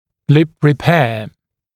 [lɪp rɪ’peə][лип ри’пэа]восстановление губы